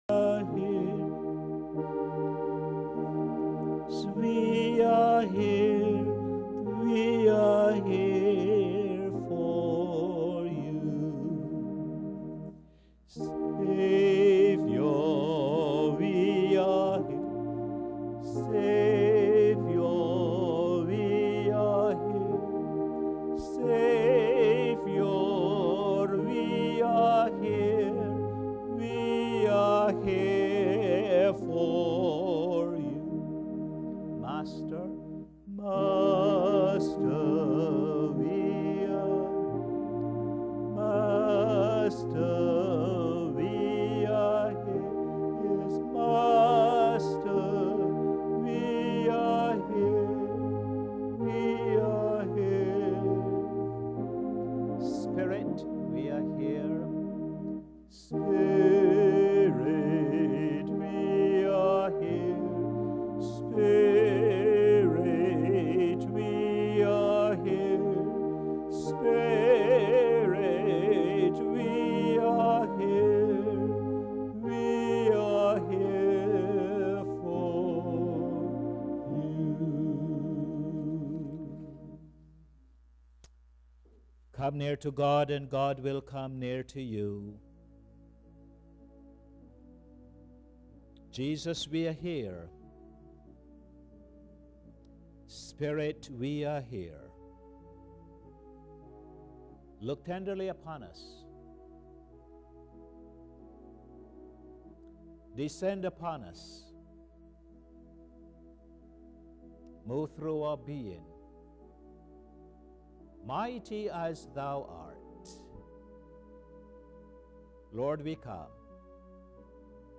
Posted in Sermons on 27.